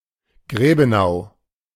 Grebenau (German pronunciation: [ˈɡʁeːbənaʊ]
De-Grebenau.ogg.mp3